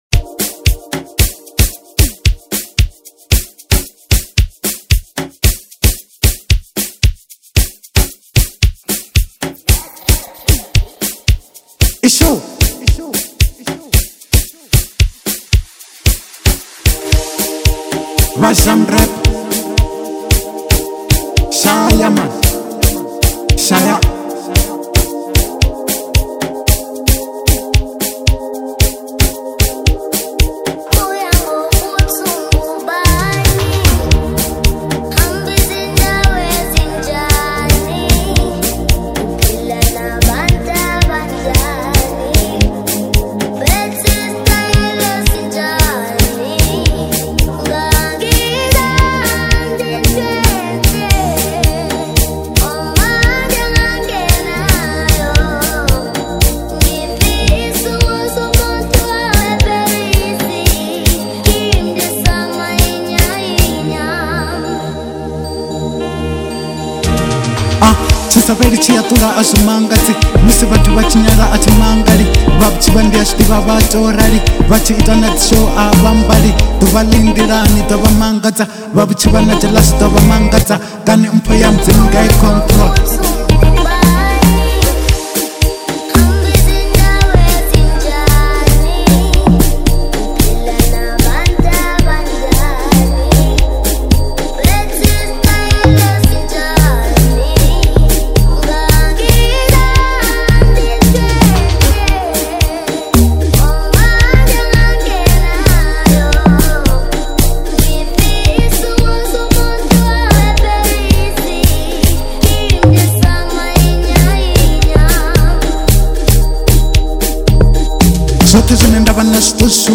04:05 Genre : Amapiano Size